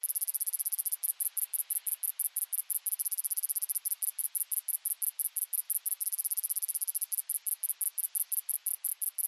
Здесь вы найдете стрекотание в разных тональностях, от одиночных особей до хора насекомых.
Стридуляция кузнечика как создается этот звук